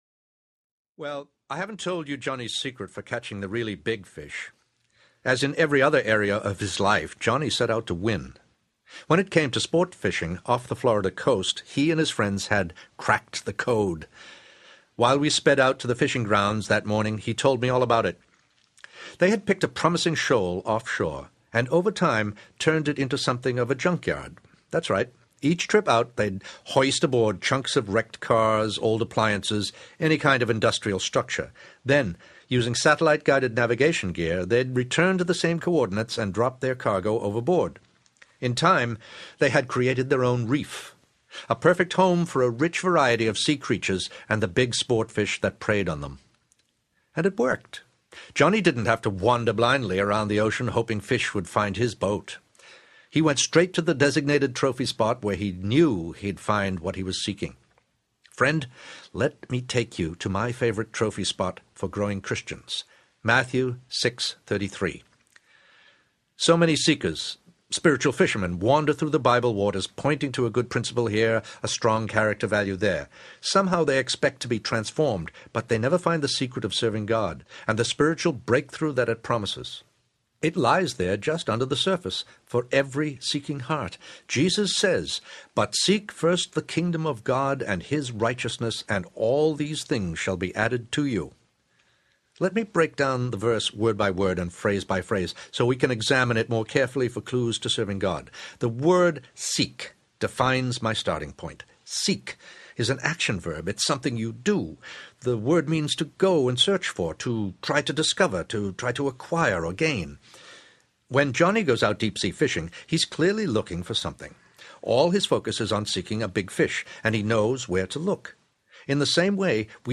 Experiencing Spiritual Breakthroughs Audiobook
Narrator
6 Hrs. – Unabridged